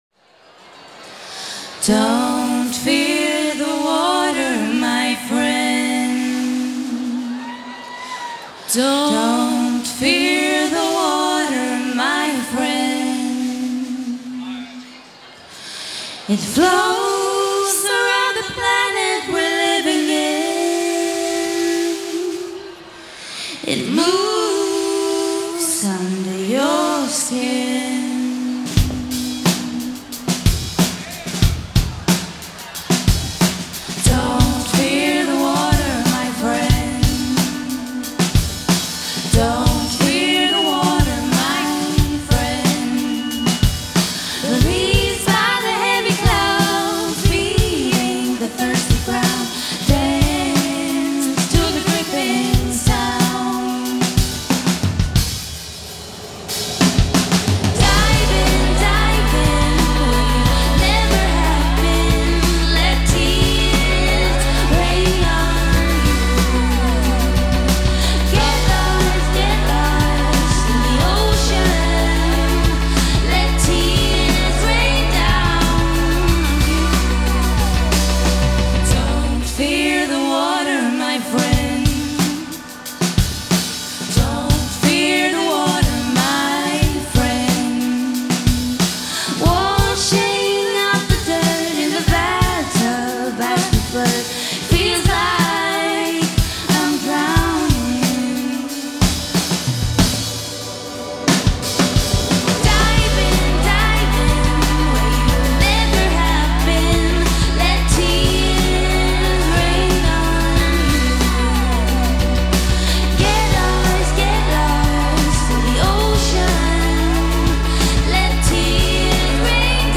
Swedish Pop
recorded live at a launch party
recorded live in Gothenburg on April 1st.